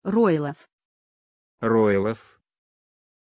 Aussprache von Roelof
pronunciation-roelof-ru.mp3